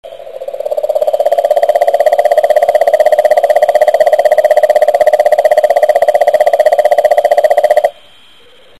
cane_toad_audio.mp3